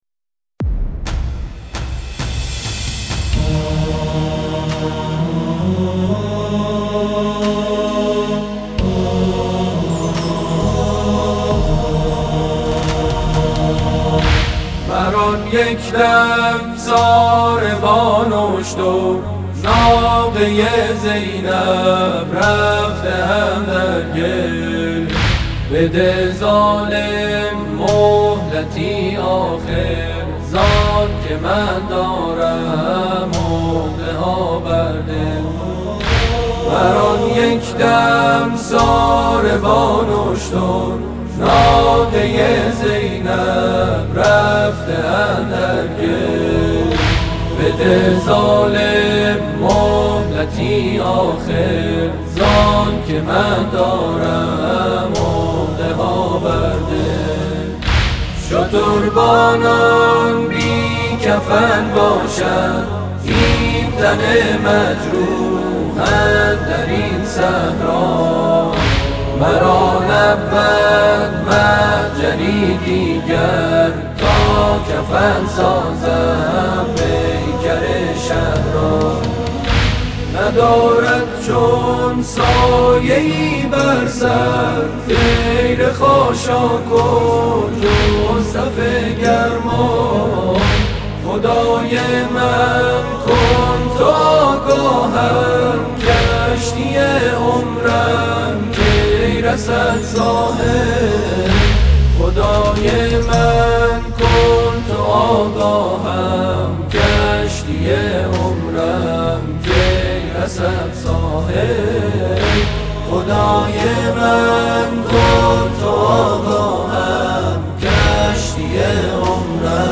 تنظیم و میکس شده‌ است